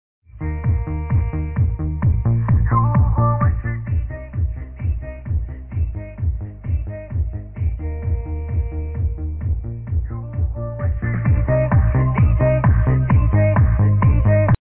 嵌入一个低音喇叭，还不错！！蹦蹦蹦！
功放：带有第二输出的音箱